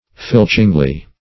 filchingly - definition of filchingly - synonyms, pronunciation, spelling from Free Dictionary Search Result for " filchingly" : The Collaborative International Dictionary of English v.0.48: Filchingly \Filch"ing*ly\, adv. By pilfering or petty stealing.
filchingly.mp3